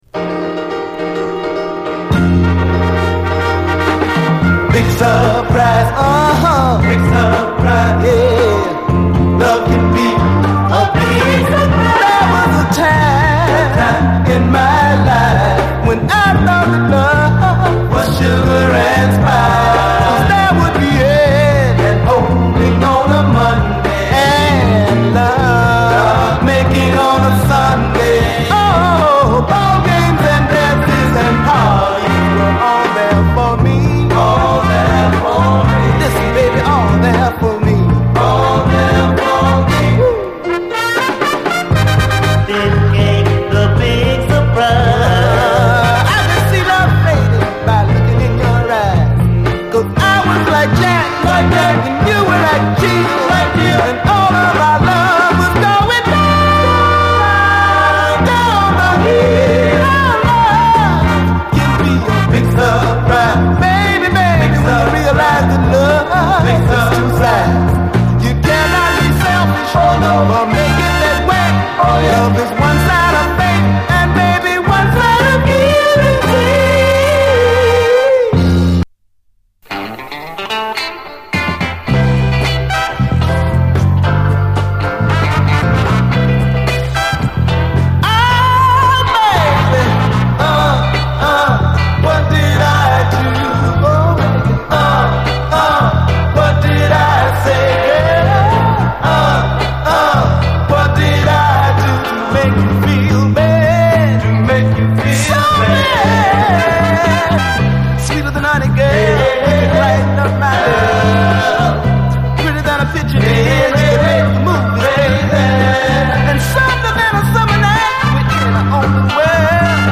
SOUL, 70's～ SOUL
ハッピーなシャッフル・ソウル